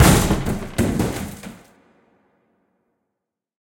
tableSlam.ogg